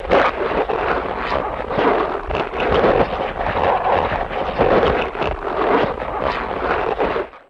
Rubber Wet Suit Removal